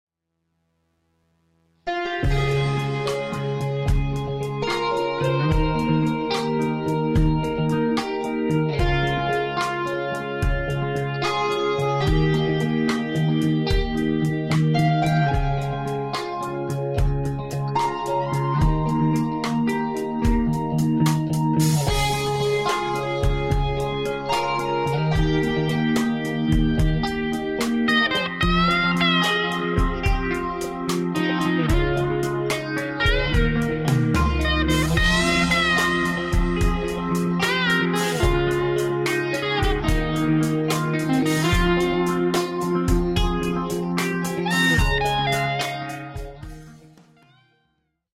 Fender Telecaster American Standard 2011, Line6 Pod HD500, Arturia Analog Experience The Laboratory 61.������ ��� � ����� �����...